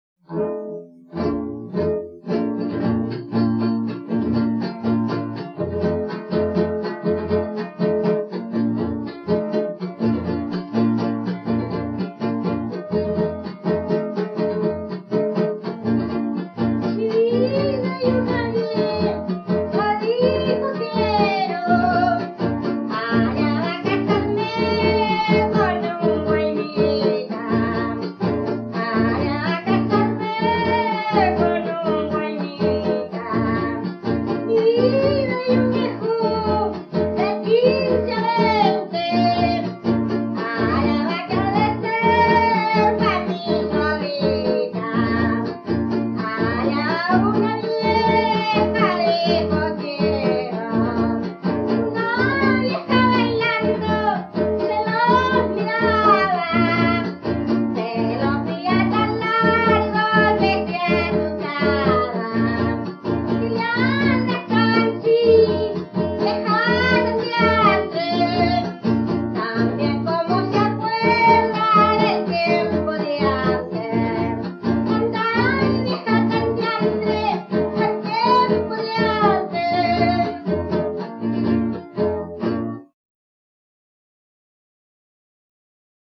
Cancionero tradicional: Una vieja dijo quiero (cueca)
Música tradicional
Folklore